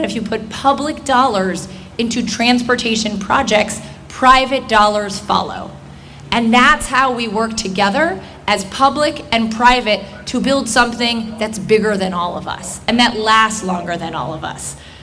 State Comptroller Brooke Lierman made her way to Western Maryland on Thursday to attend the ribbon cutting for the new Cumberland Downtown. In her remarks, Lierman said the downtown project is a great example of government and the private sector working together…